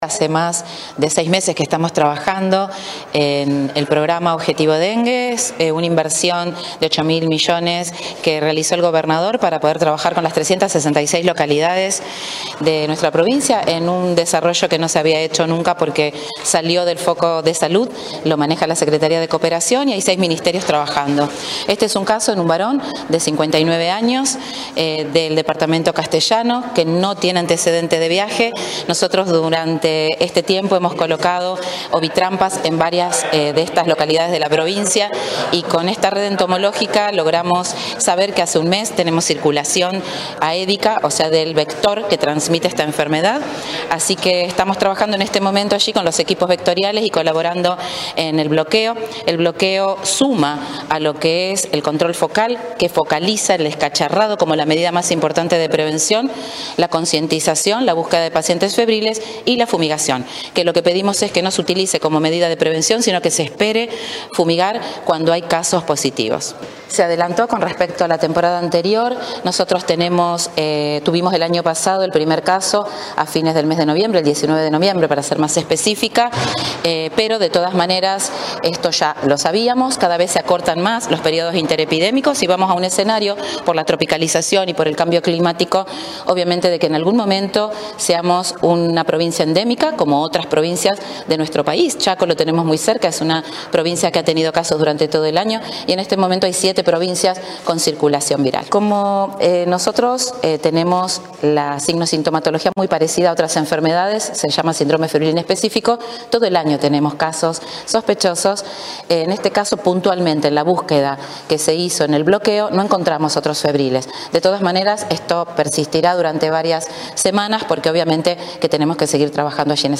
La funcionaria, en una rueda de prensa desarrollada este jueves en la sede del Ministerio de Salud en Santa Fe, agregó que “logramos saber que hace un mes que tenemos circulación aédica, o sea del vector que transmite esta enfermedad. Estamos trabajando con los equipos vectoriales y colaborando en el bloqueo, que focaliza en el descacharrado como la medida más importante de prevención, la concientización, la búsqueda de pacientes febriles y la fumigación, que lo que pedimos es que no se utilice como medida de prevención sino que se espere a fumigar cuando haya casos positivos”.